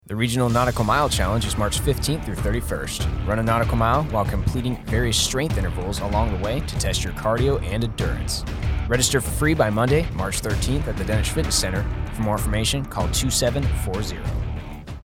A radio spot informing listeners of the Southeast regional Nautical Mile Challenge on NAS Guantanamo Bay.